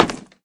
wood_place.ogg